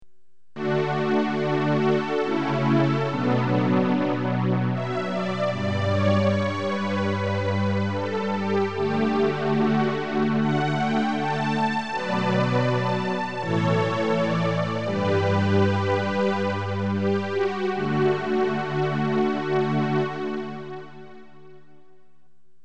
synthex_strings.mp3